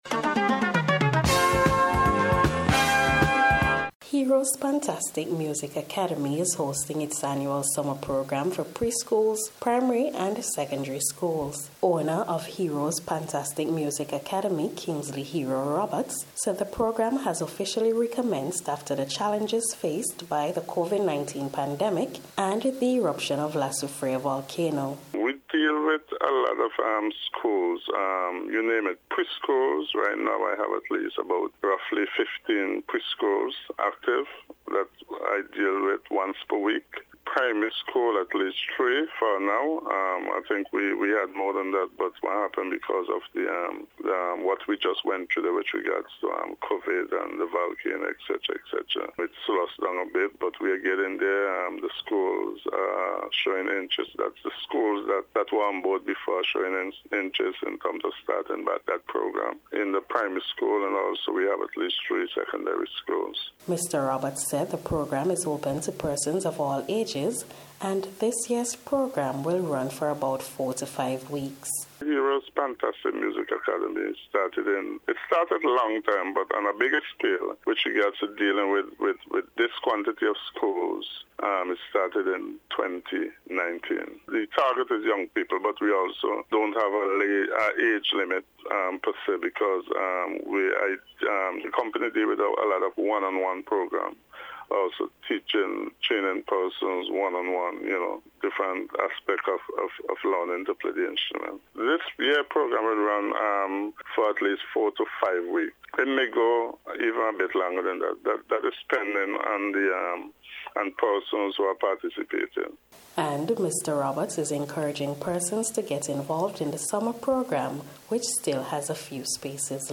NBC’s Special Report- Tuesday July 19th 2022